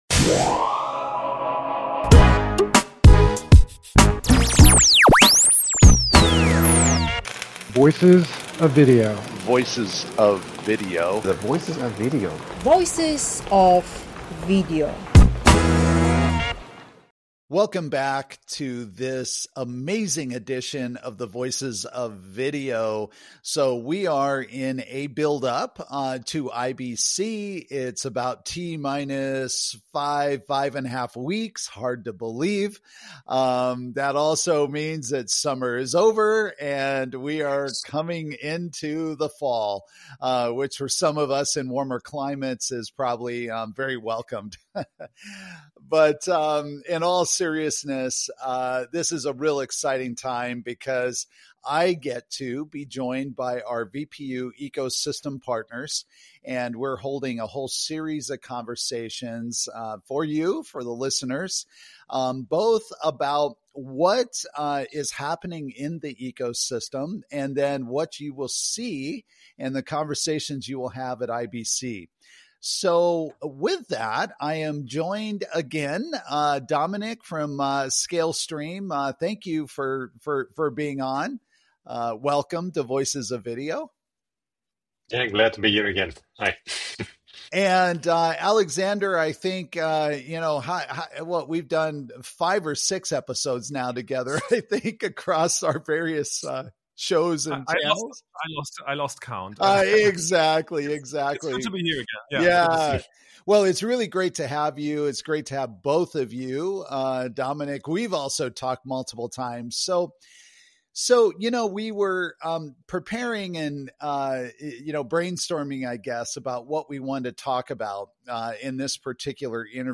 The panel dives into real-world challenges and lessons learned from high-profile deployments— including G&L’s streaming platform for the European Parliament— and discusses how to tackle complex processing tasks such as deinterlacing 4K content and handling more than 320 audio channels in parallel.